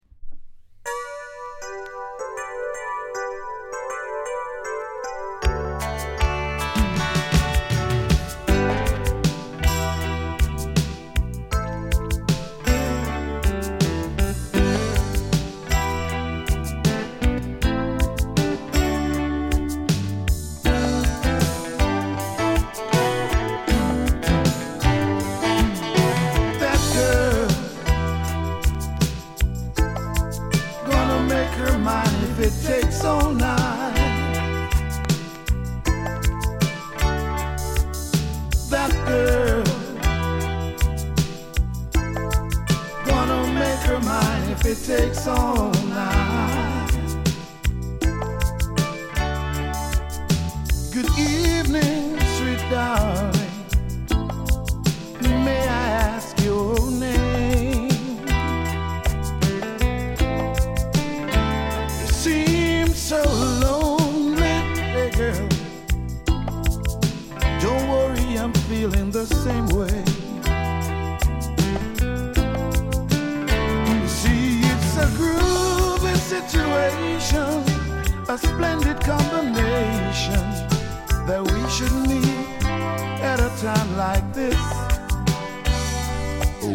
SOUL作品